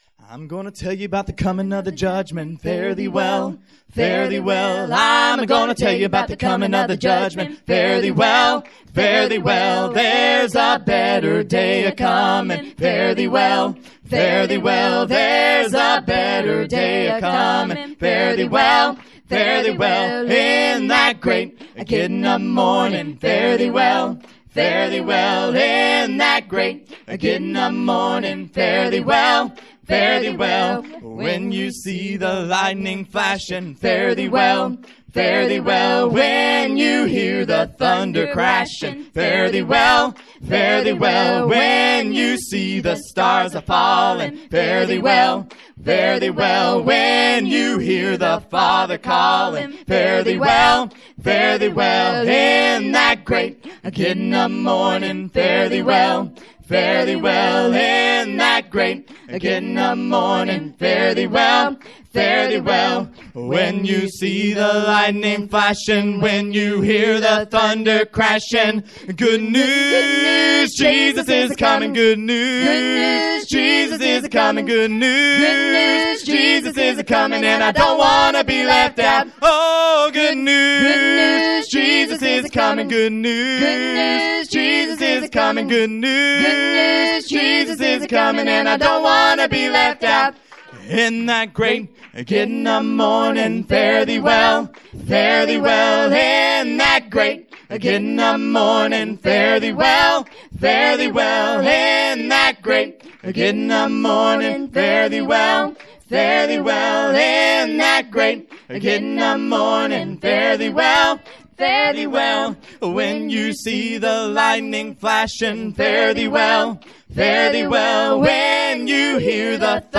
Perfected Love Holiness Church - Songs & Instrumental
In That Great Gettin’ Up Morning – [Acapella] – Perfected Love Holiness Church – 04/20/2018